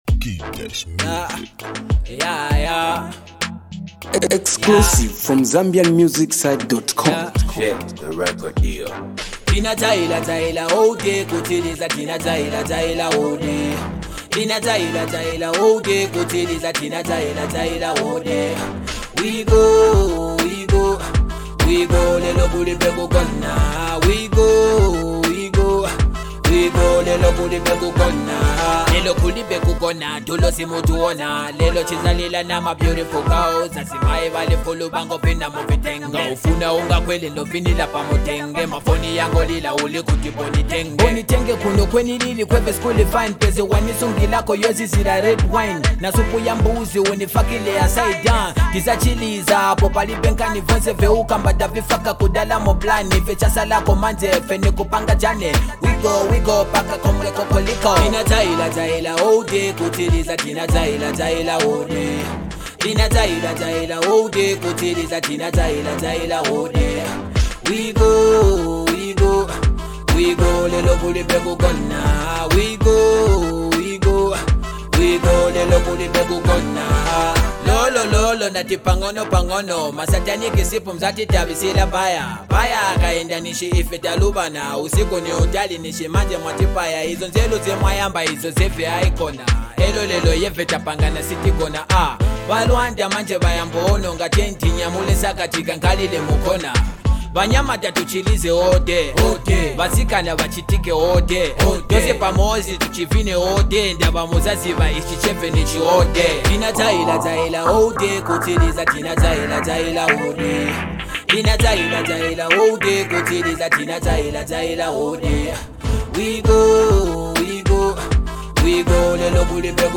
it’s a great song with a good vibe